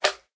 magmacube